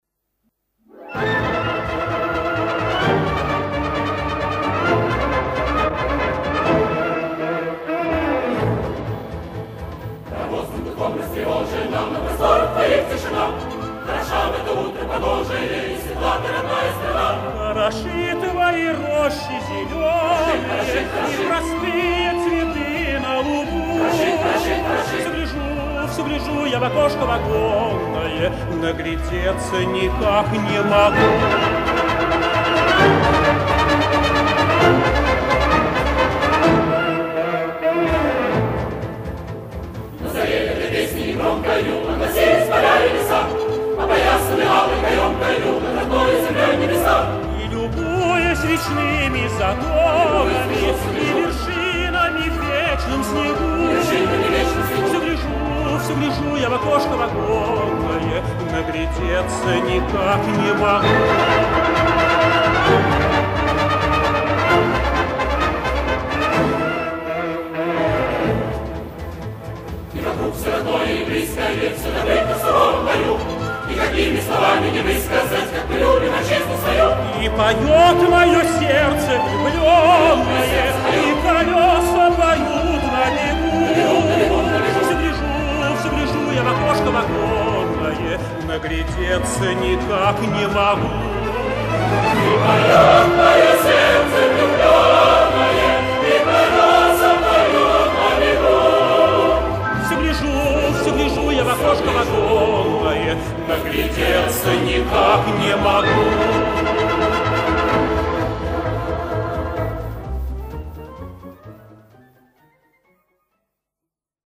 Источник Из личной коллекции певца